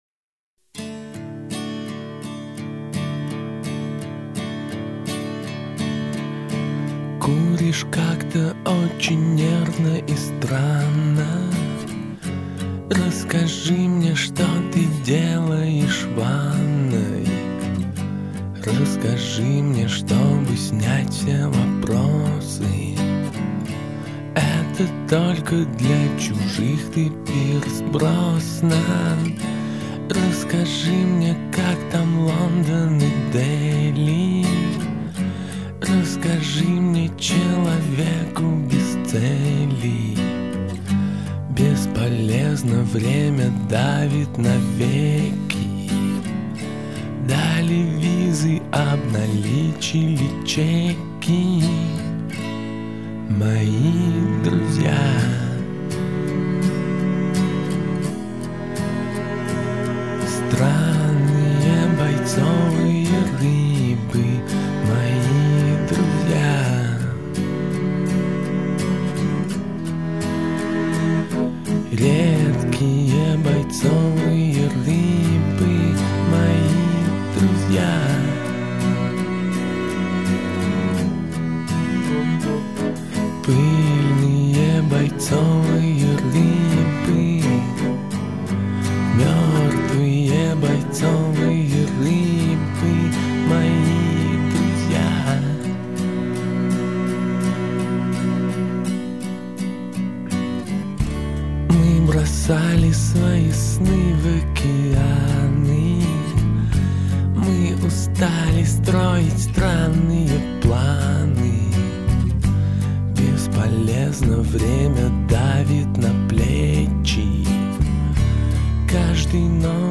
Такт 4/4